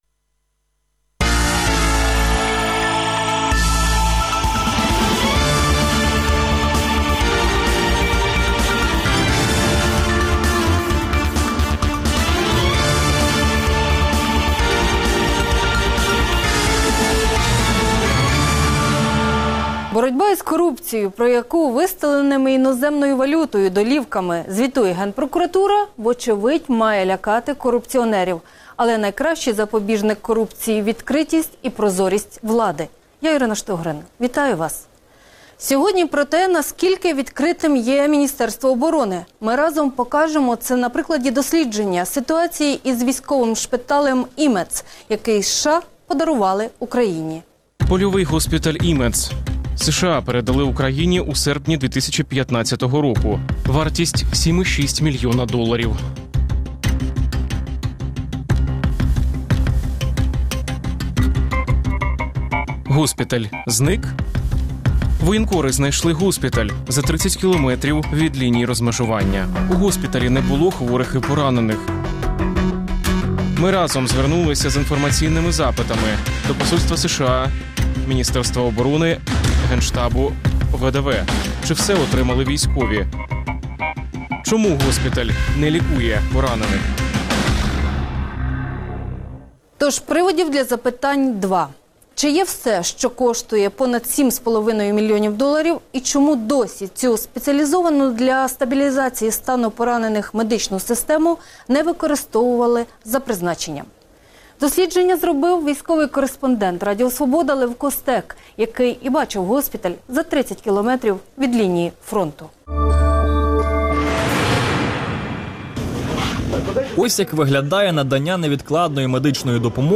Гість: Андрій Верба , директор Військово - медичного департаменту Міністерства оборони України